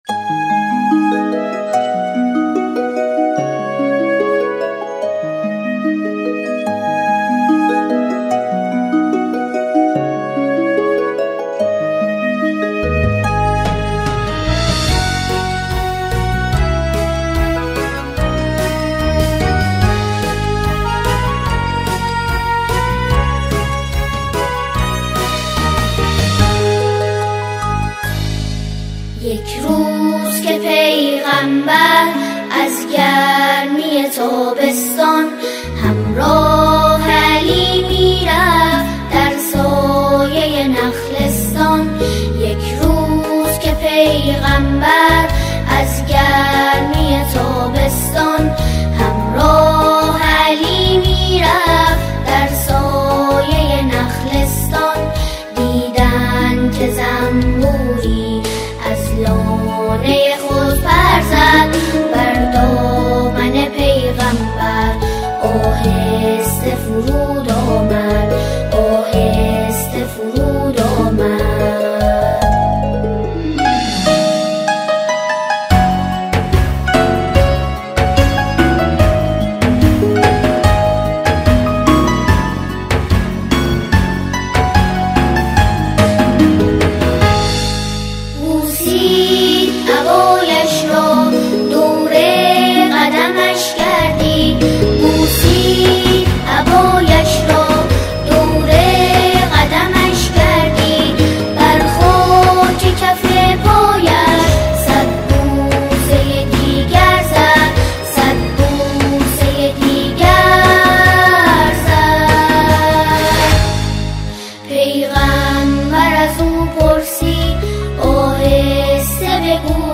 شعر کودک کارتون (انیمیشن)